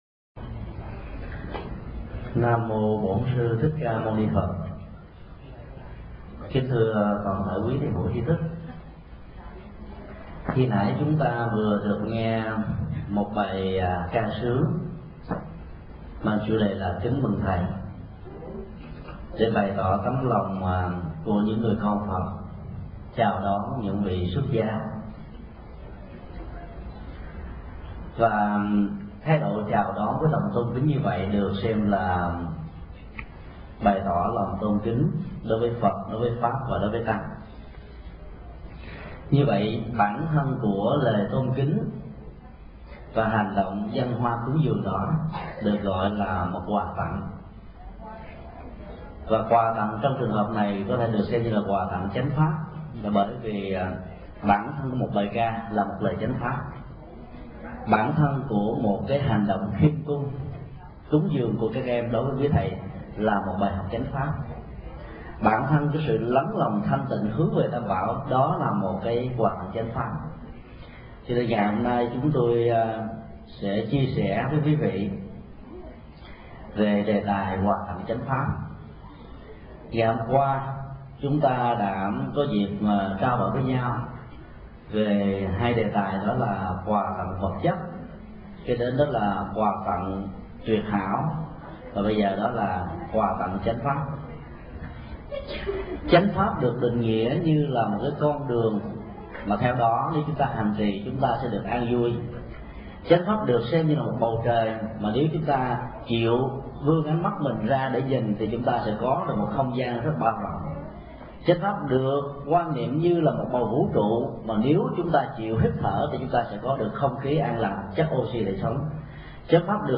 Mp3 Pháp Thoại Quà tặng chánh pháp
Chùa Hải Đức, Jacksonville